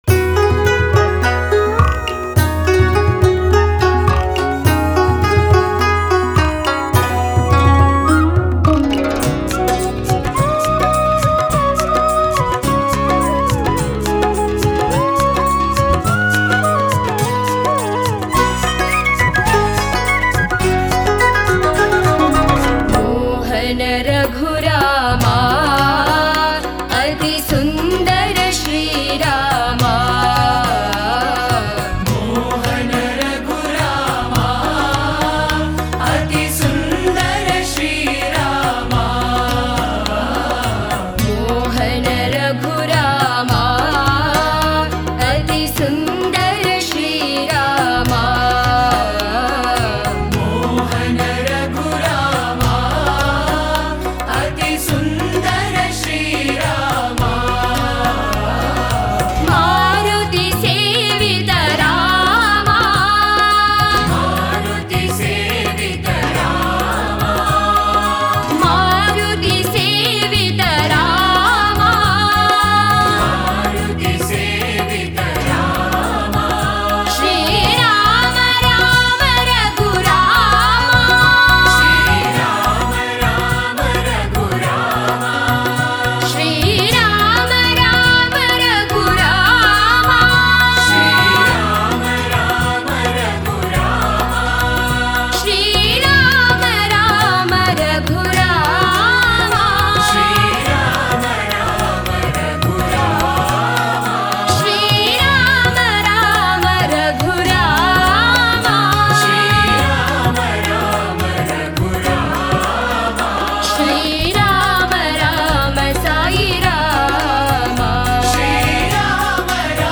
Author adminPosted on Categories Rama Bhajans